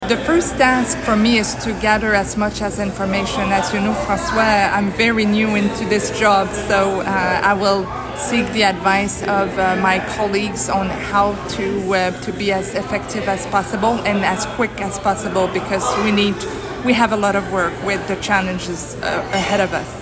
Following her commanding win, Chatel spoke with CHIP 101.9 about the campaign and her next steps as the region’s representative.